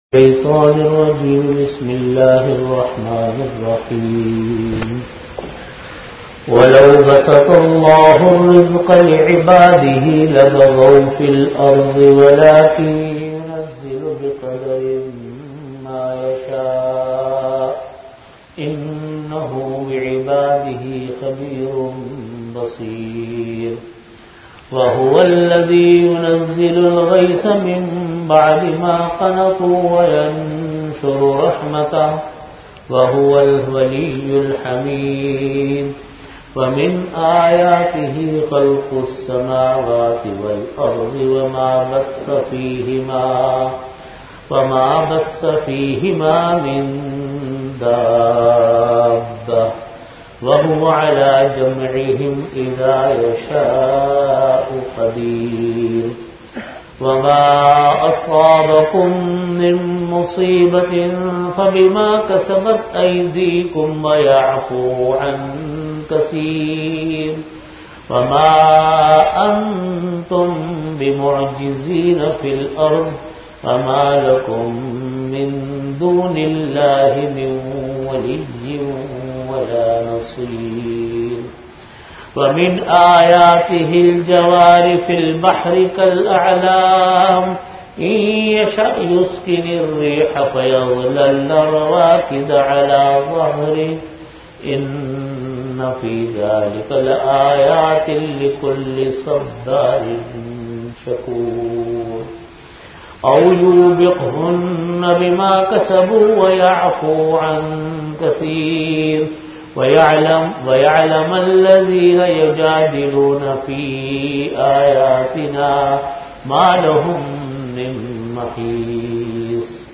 Tafseer · Jamia Masjid Bait-ul-Mukkaram, Karachi